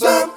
Hip Vcl Kord-C.wav